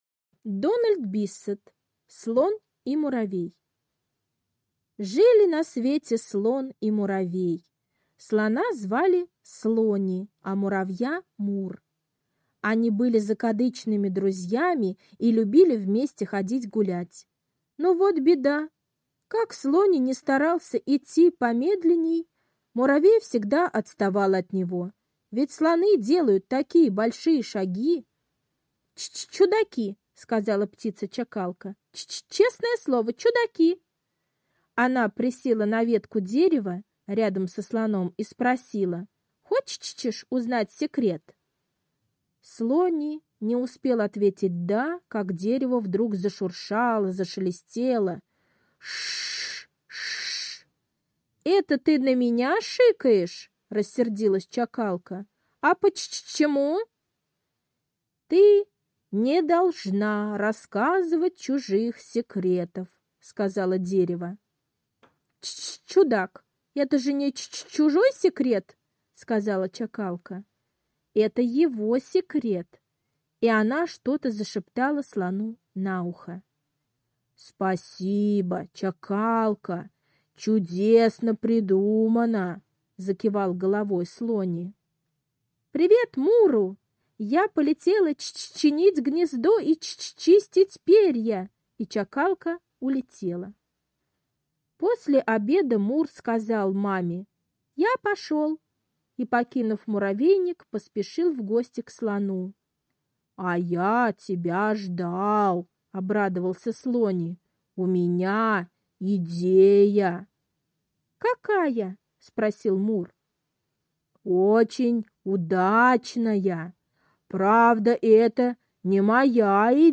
Слон и муравей - аудиосказка Биссета Д. Сказка про то, как птичка-чакалка помогла закадычным друзьям слону и муравью.